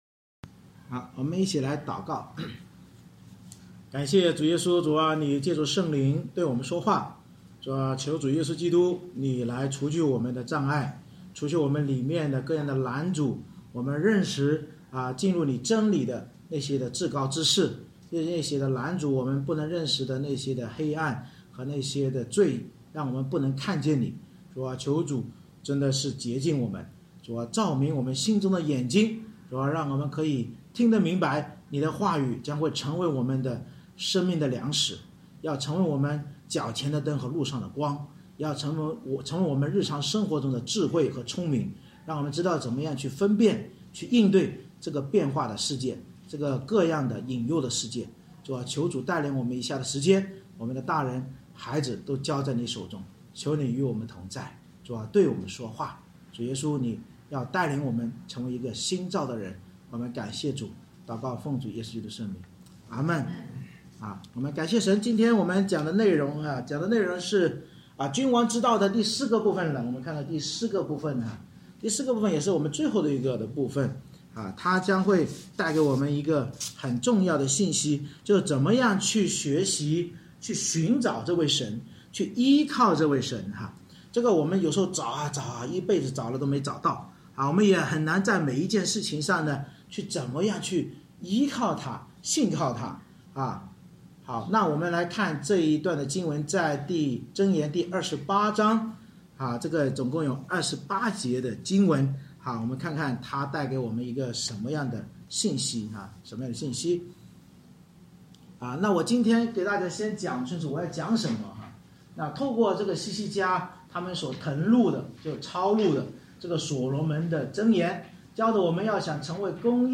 《箴言》讲道系列 Passage: 箴言28章 Service Type: 主日崇拜 通过希西家的人所誊录的所罗门箴言，教导我们要想成为公义而繁荣强盛的君王或管理者，就必须在心灵言行各方面寻求并依靠主耶稣基督。